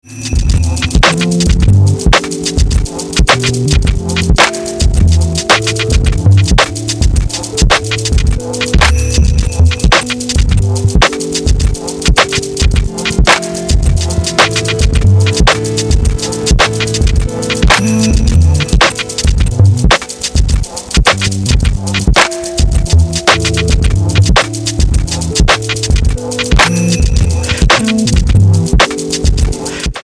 Superb abstract beats